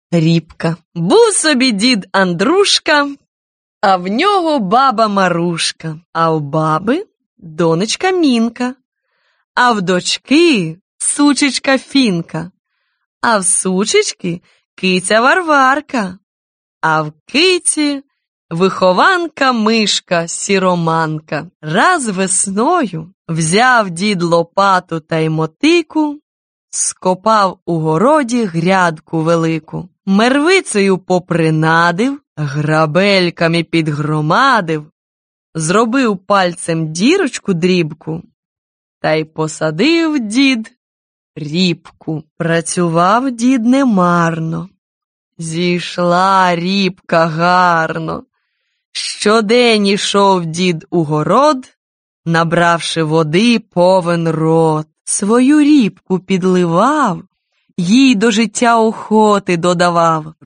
Аудиокнига Українські побутові казки. Випуск 1 | Библиотека аудиокниг